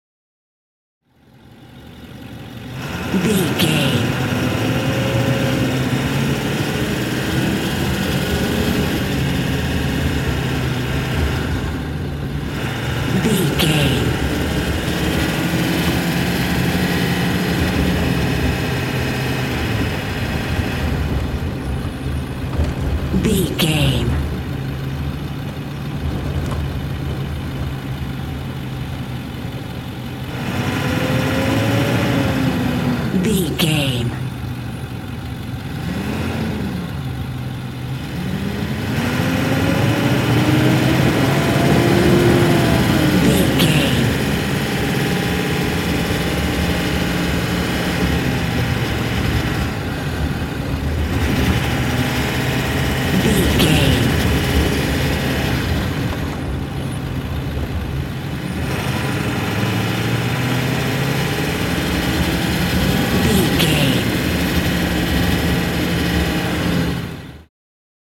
Ambulance Int Drive Diesel Engine Accelerate Fast
Sound Effects
urban
chaotic
emergency